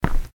Footstep_Tile_Left.wav